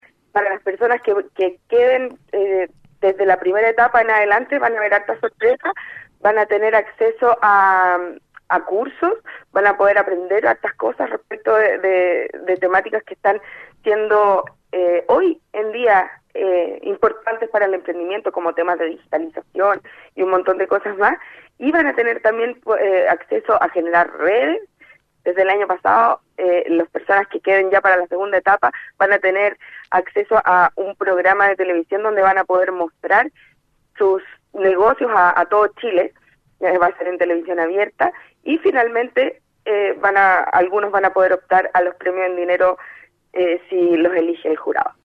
cuna-01-concurso-emprendedor-levantemos-chile.mp3